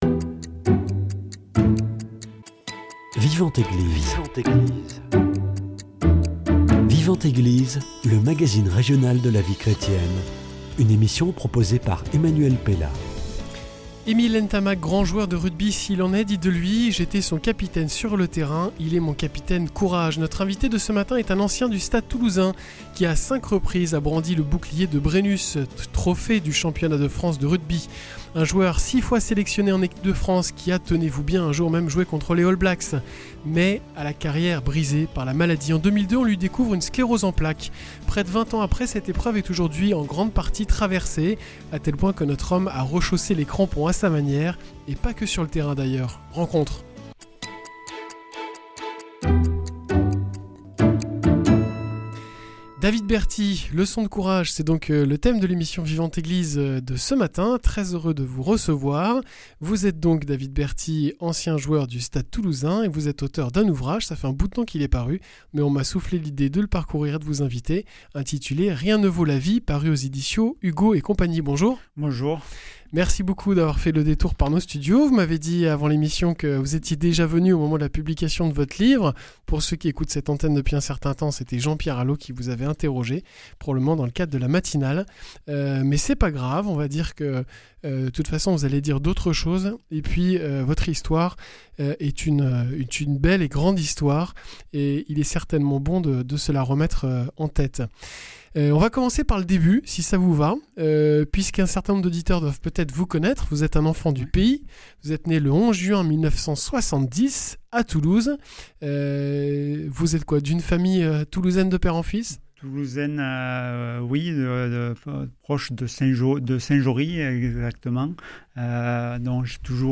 [ Rediffusion ] (Rediffusion) Il a brandi avec le Stade Toulousain le bouclier de Brennus à cinq reprises, a joué en équipe de France, notamment contre les All Blacks. Ailier de talent, notre invité a vu sa carrière brisée par une sclérose en plaques.